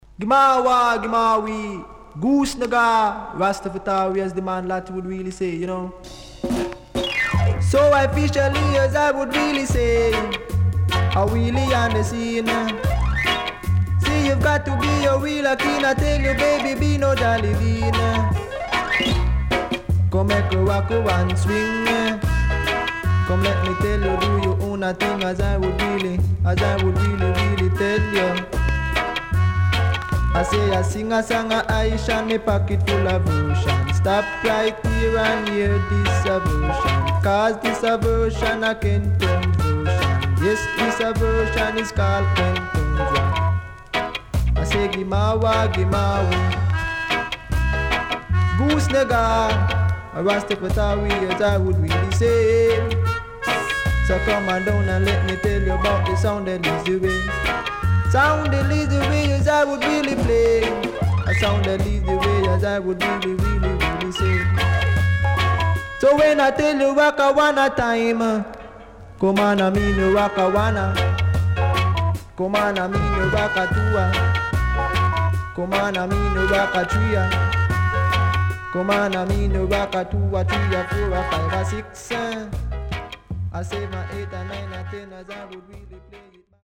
HOME > REGGAE / ROOTS  >  KILLER & DEEP  >  70’s DEEJAY
SIDE A:所々プチノイズ入ります。